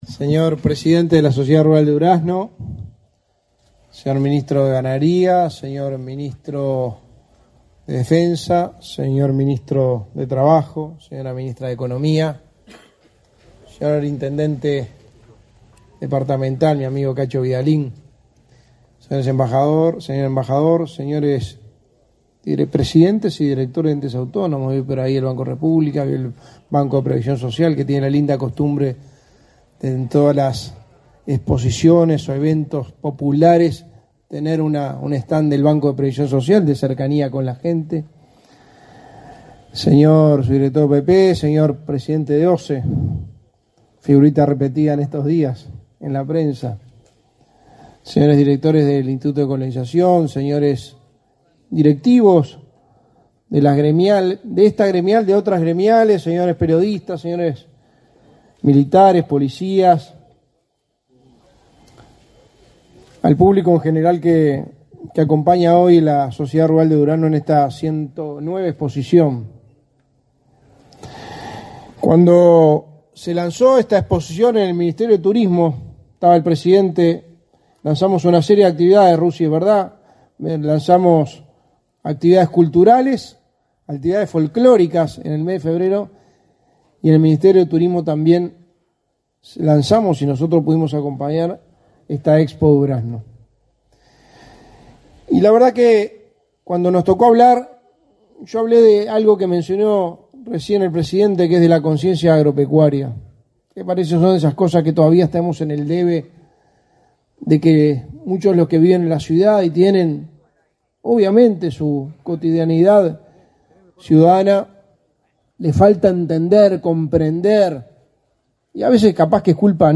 Palabras del secretario de la Presidencia, Álvaro Delgado
El secretario de la Presidencia, Álvaro Delgado, participó, este 23 de febrero, en la 109.ª edición de la Expo Durazno.